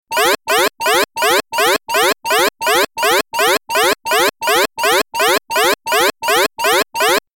دانلود آهنگ هشدار 21 از افکت صوتی اشیاء
دانلود صدای هشدار 21 از ساعد نیوز با لینک مستقیم و کیفیت بالا
جلوه های صوتی